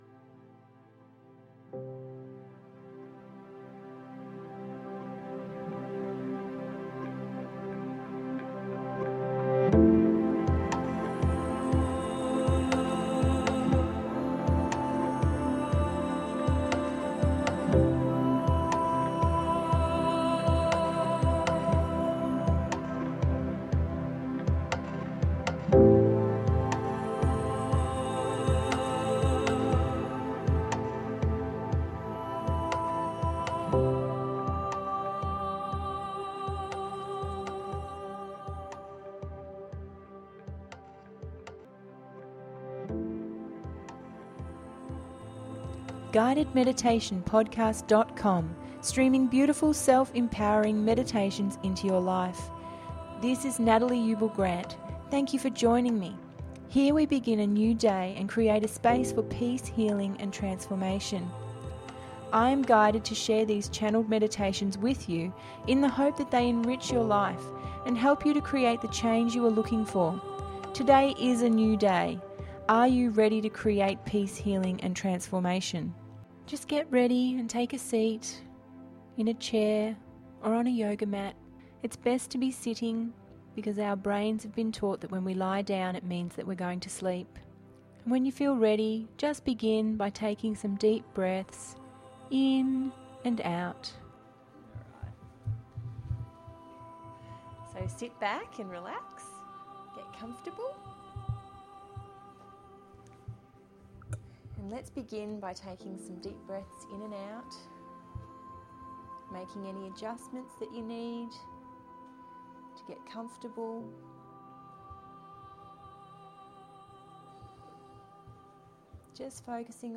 Animal Guide To Higher Self Meditation…036 – GUIDED MEDITATION PODCAST